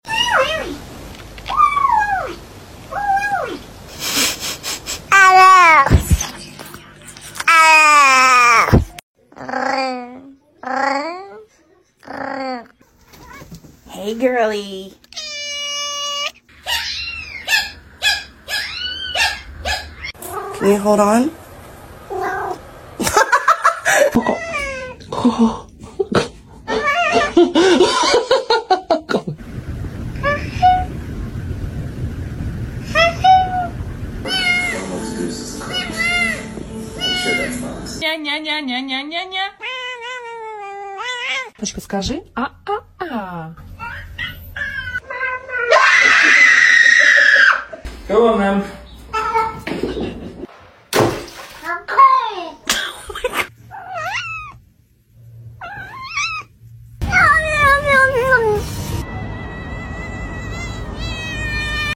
Part 69 | Funny Cat Sound Effects Free Download